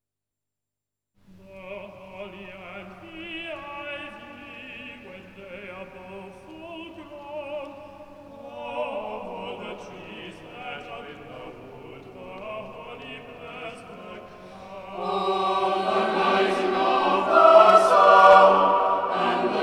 Extracts from live recordings
tenor
bass